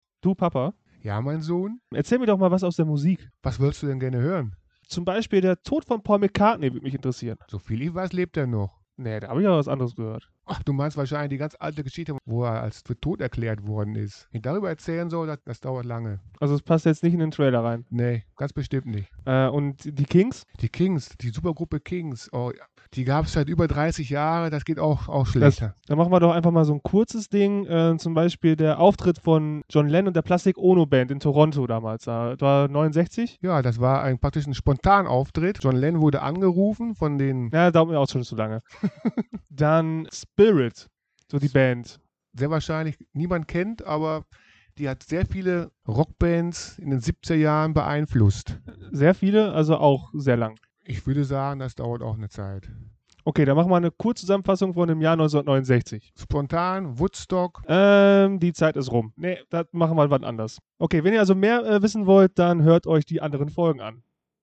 Trailer
Kurz, knapp und unheimlich gehetzt gibt es hier einen kleinen